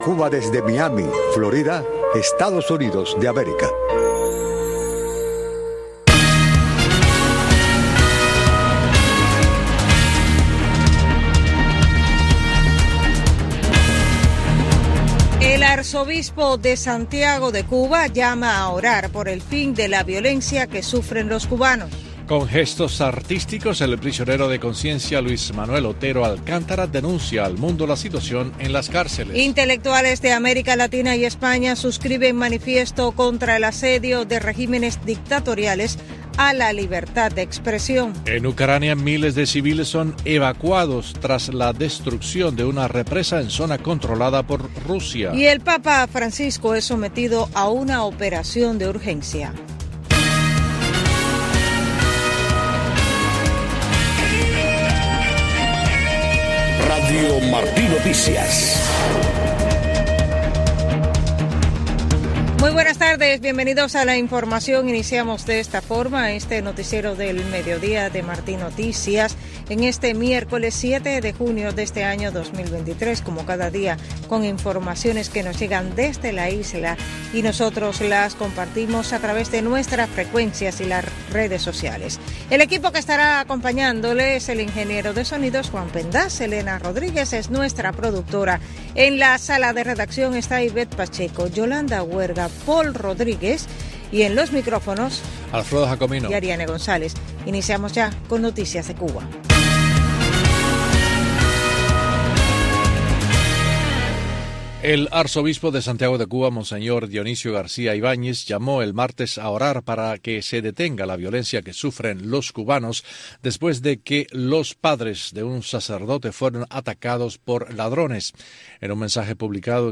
Noticiero de Radio Martí 12:00 PM | Primera media hora